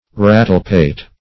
Rattlepate \Rat"tle*pate`\ (r[a^]t"t'l*p[=a]t`), n.
rattlepate.mp3